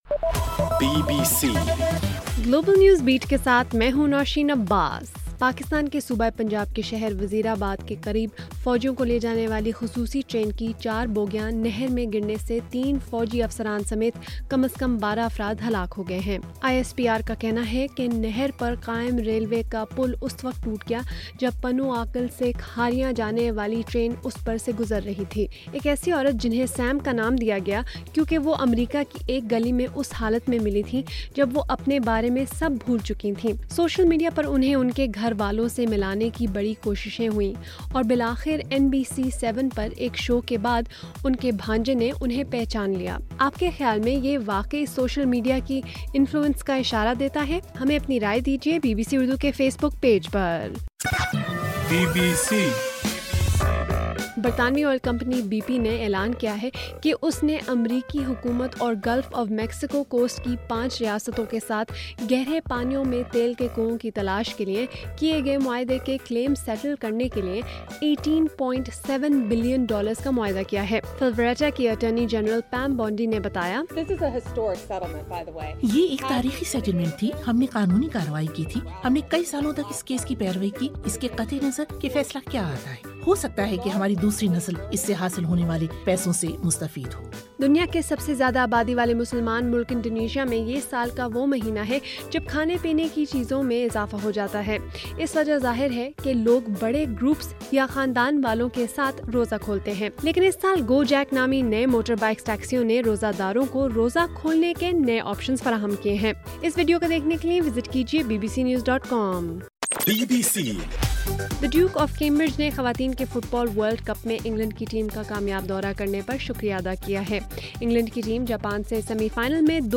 جولائی 3: صبح1 بجے کا گلوبل نیوز بیٹ بُلیٹن